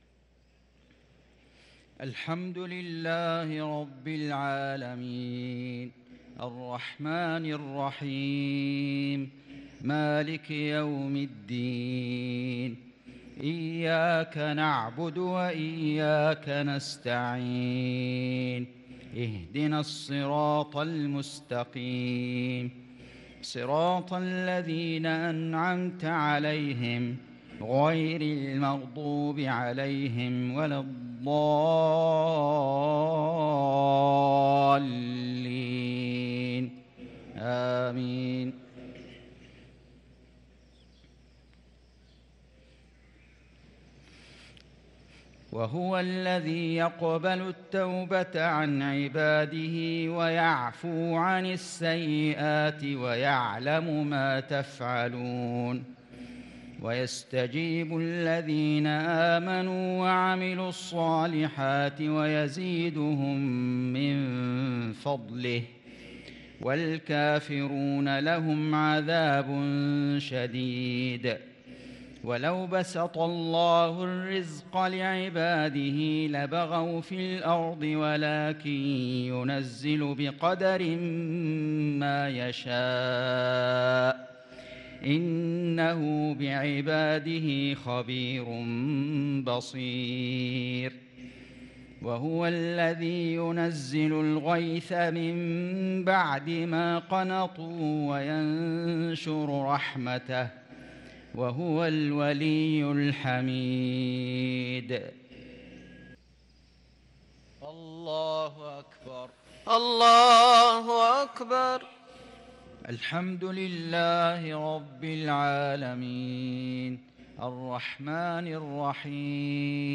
تِلَاوَات الْحَرَمَيْن .